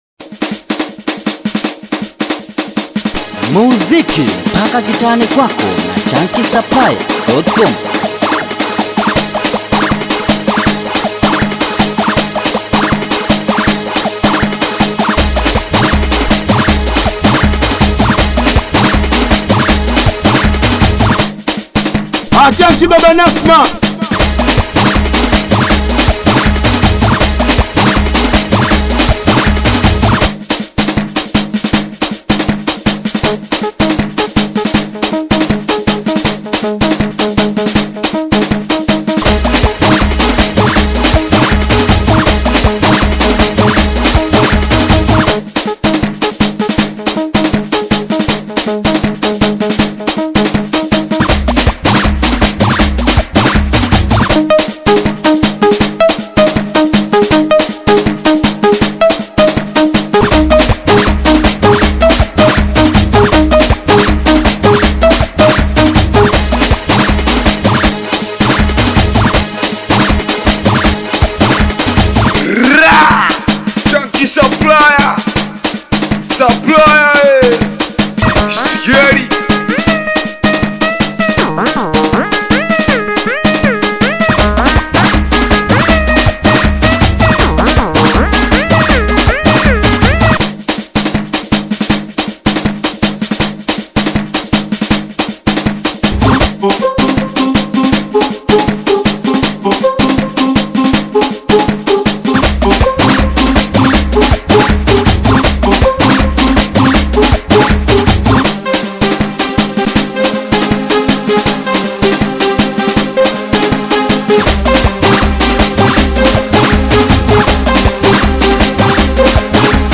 BITI SINGELI • SINGELI BEAT